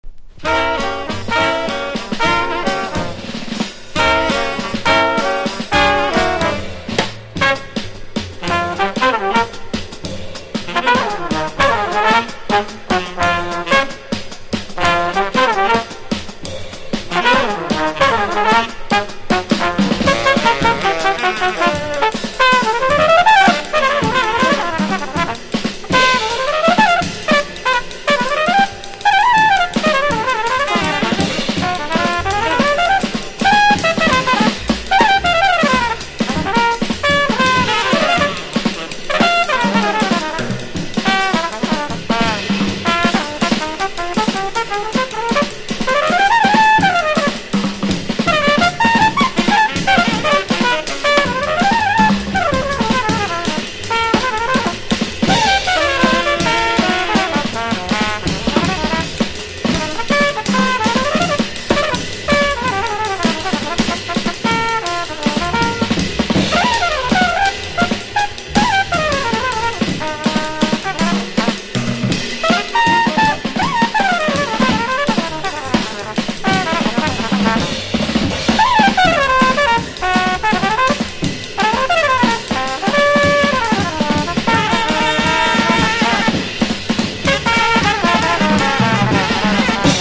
テナー奏者